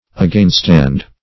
Againstand \A*gain"stand`\, v. t.